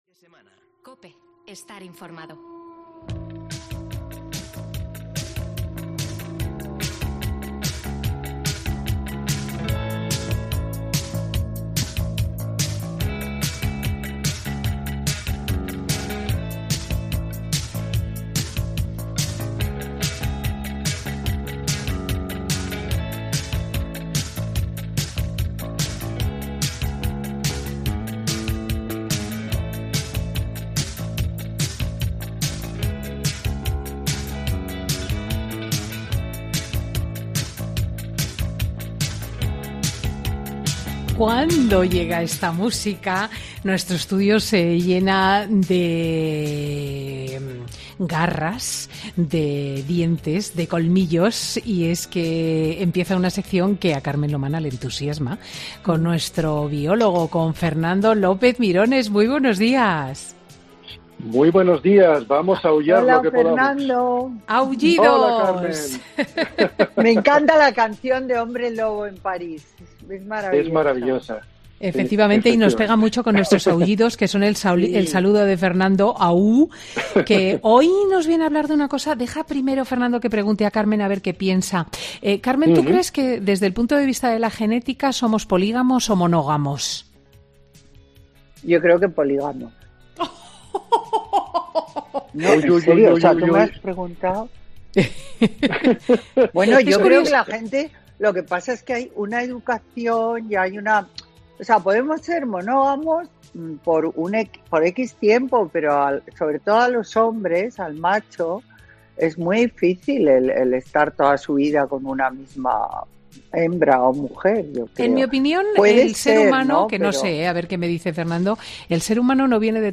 AUDIO: Fernando López-Mirones habla en Fin de Semana con Cristina de cómo un virus ha cambiado esta tendencia en topillos de pantano